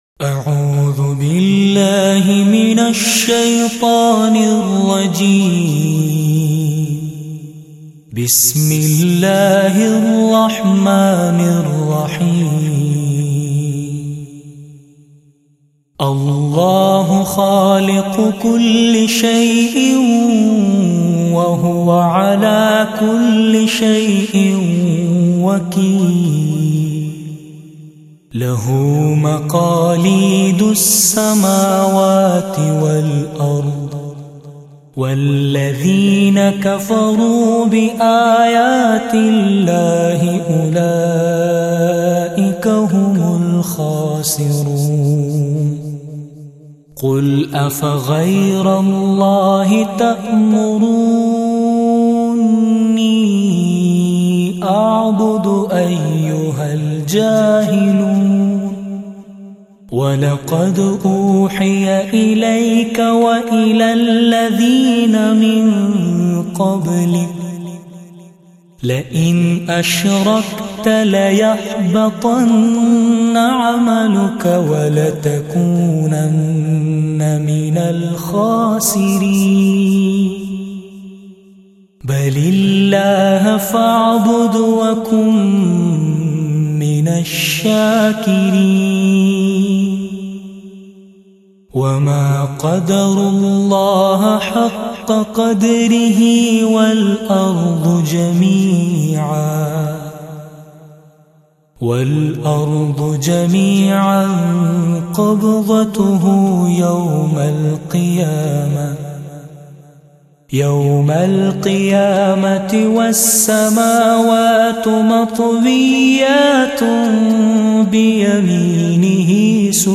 Tilawat E Quran Pak